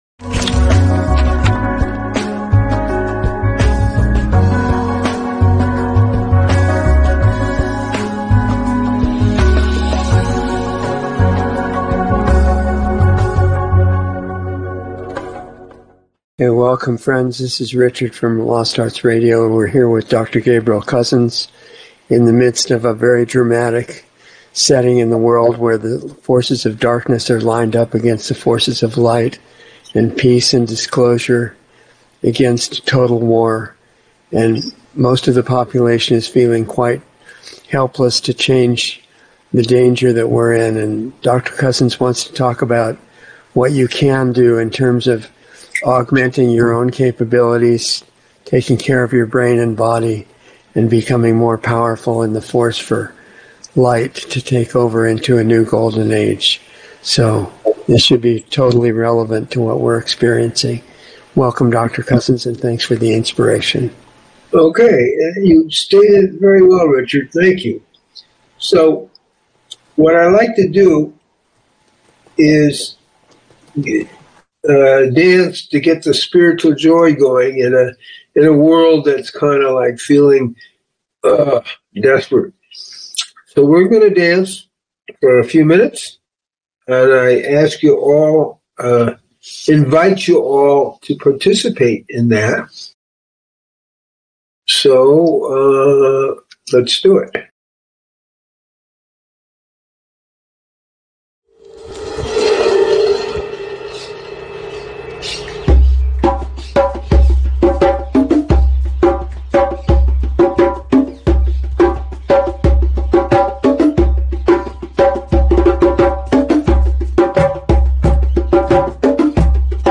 How To Make Your Brain Bigger - Dialogs